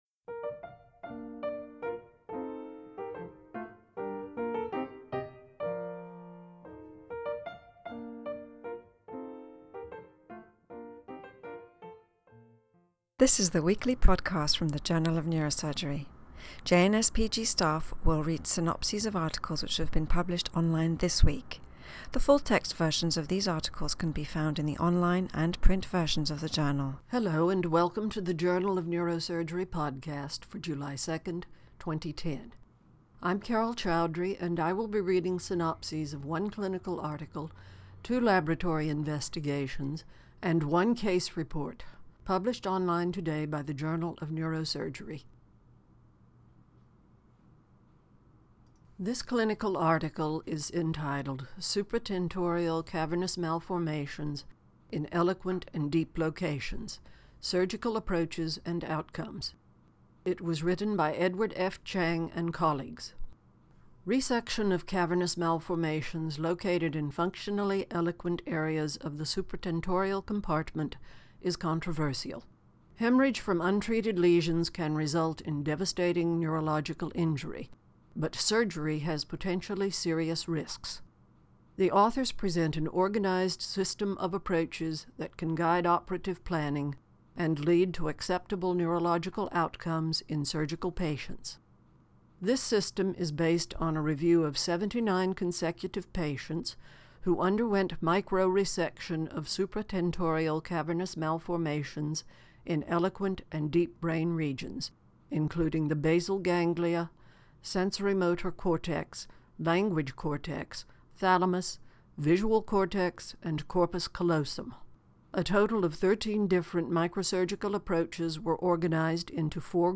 reads synopses of Journal of Neurosurgery articles published online on July 2, 2010.